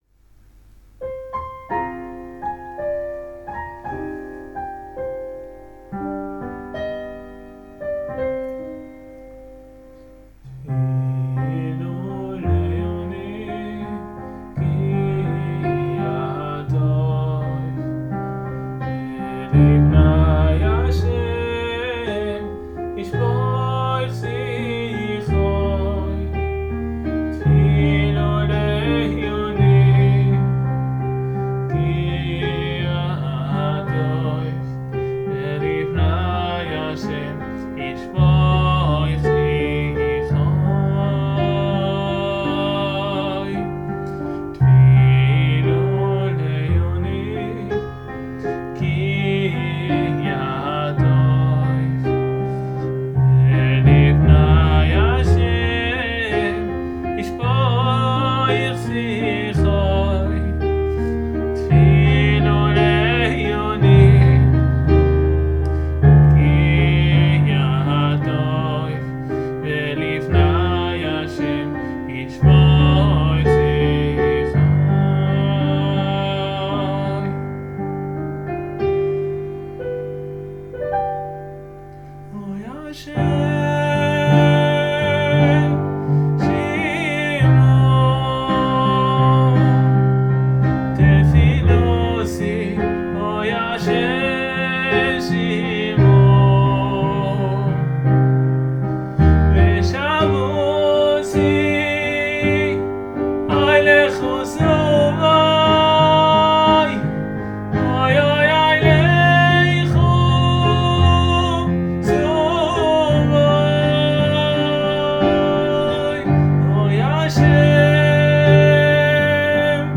יש כאן שילוב של קצת חסידי וקצת ארץ ישראלי של פעם
כי הפסנתר נשמע – לא משהו